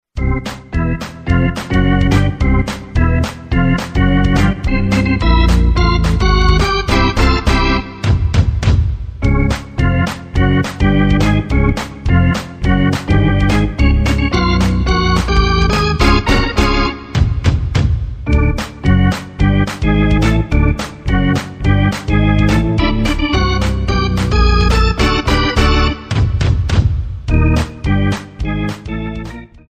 Category: Sports   Right: Personal